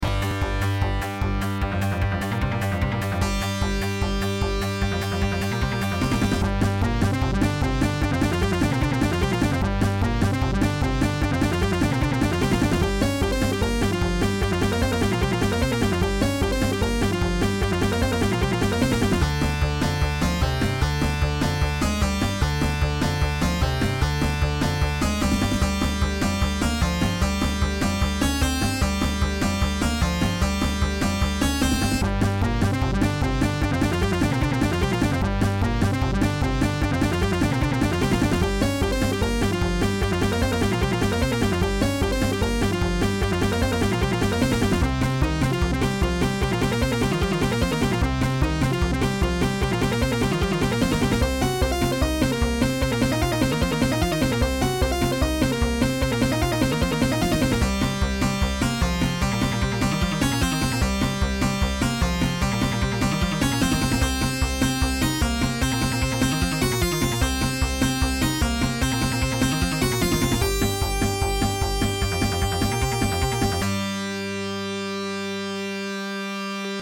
I still enjoy doing Genesis chiptune music, and there's a contest kicking off, so I thought, why not?
music genesis chiptune boss battle fun